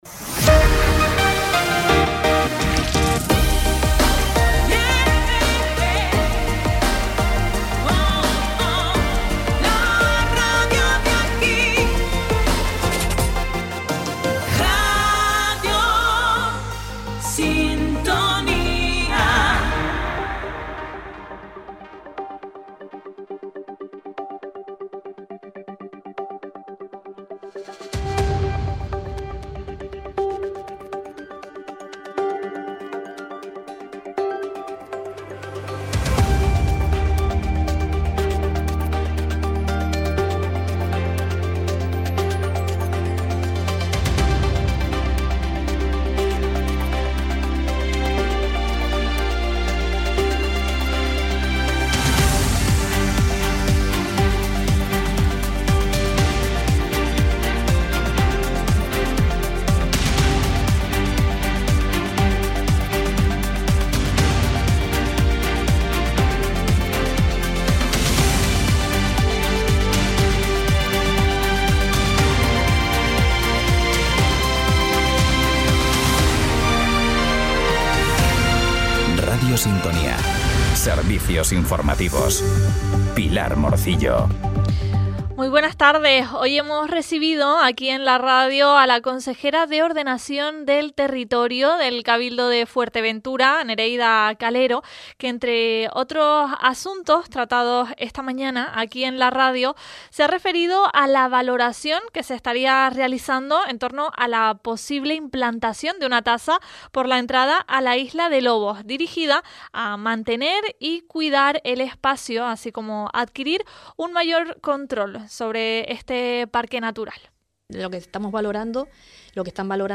Cada día, desde Radio Sintonía, puedes seguir toda la información local y regional en nuestro espacio informativo. En él te contamos, en directo, las noticias más importantes de la jornada, a partir de las 13:15h.